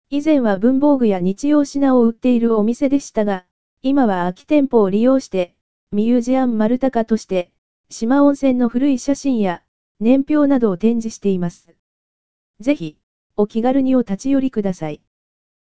まるたか – 四万温泉音声ガイド（四万温泉協会）